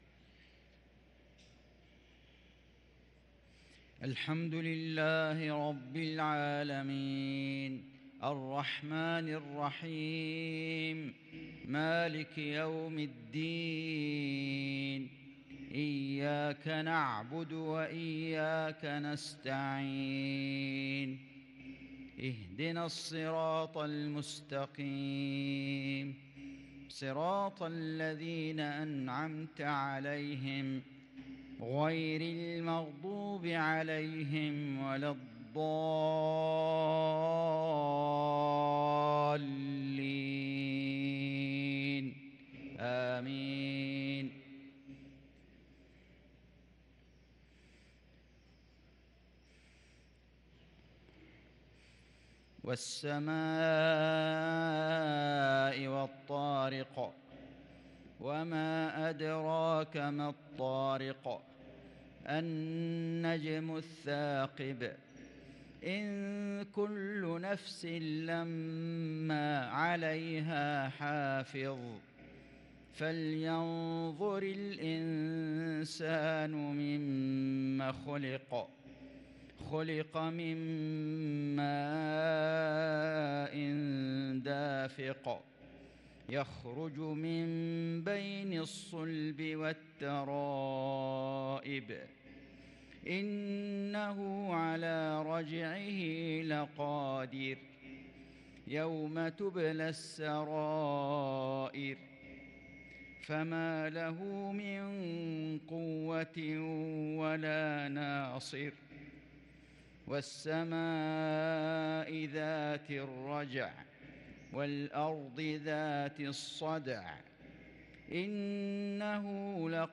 صلاة المغرب للقارئ فيصل غزاوي 15 ربيع الآخر 1444 هـ
تِلَاوَات الْحَرَمَيْن .